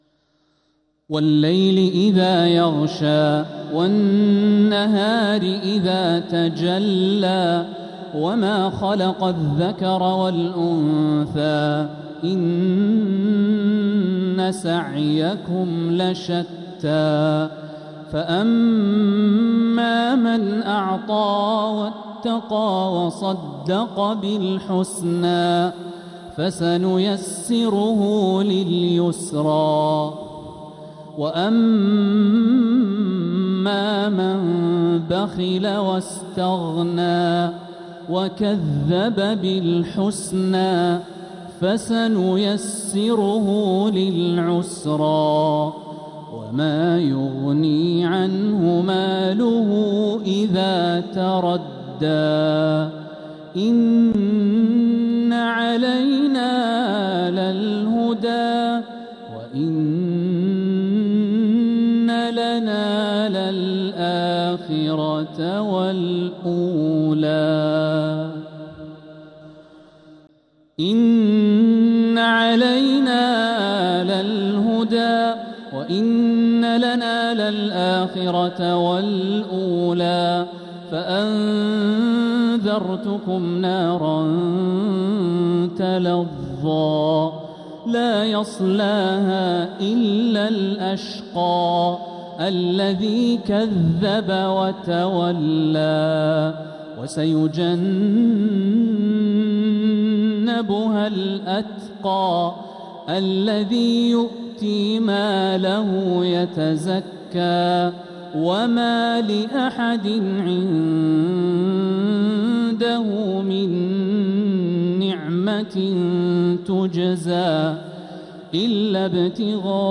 السور المكتملة 🕋